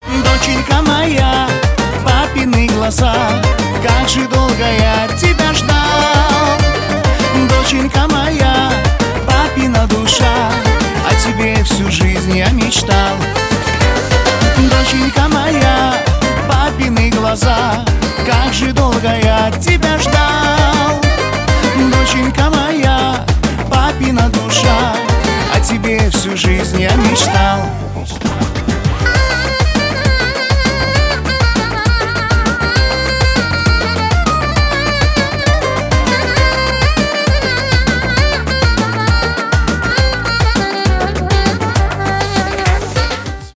кавказские
шансон